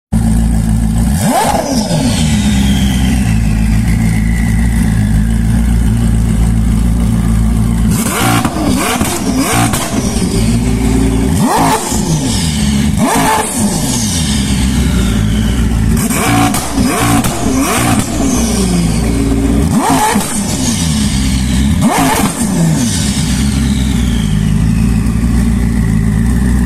Koenigsegg Jesko Jesko Absolut